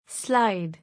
slide.mp3